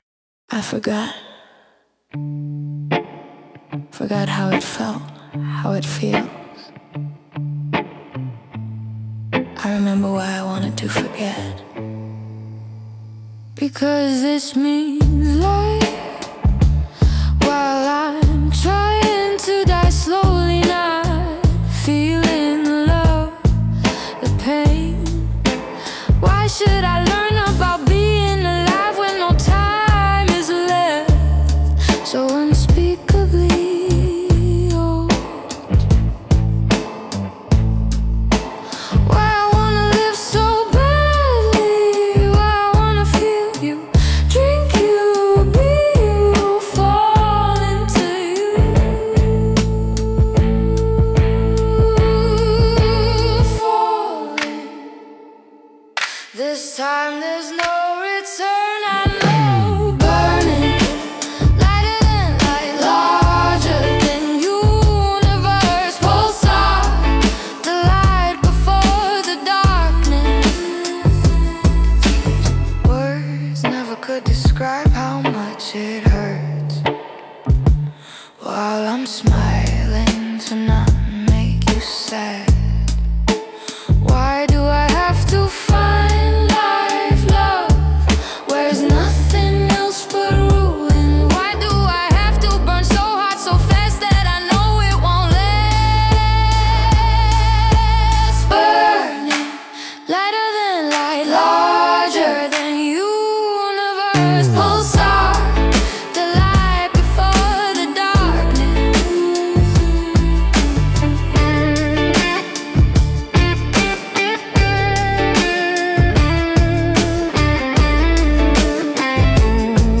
A voice in the dunes, falsetto heat and rhythmic depth.